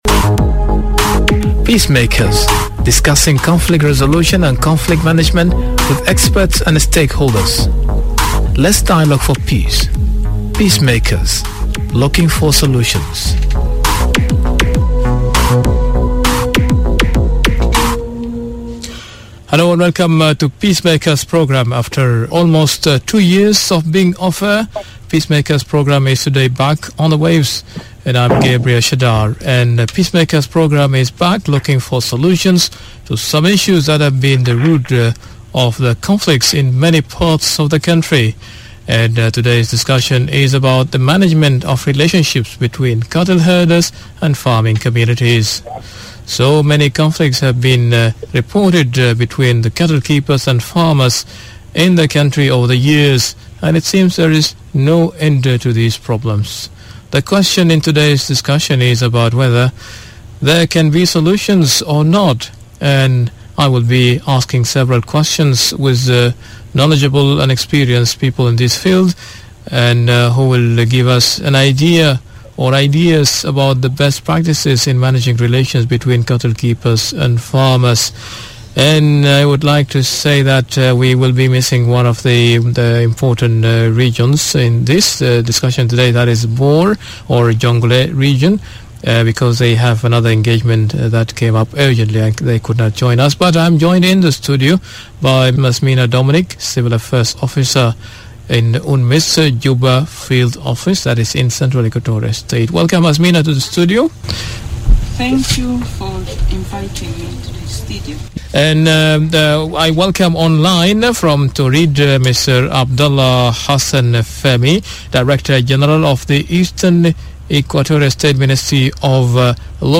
Today’s discussion is about management of relationships between cattle herders and farming communities.